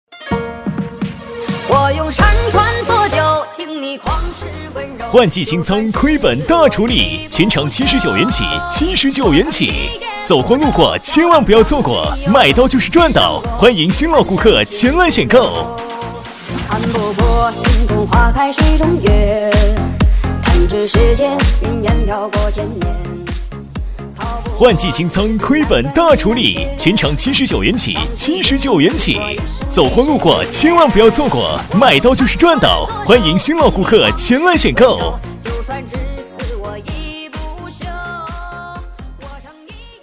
100%人工配，价格公道，配音业务欢迎联系：
C男193号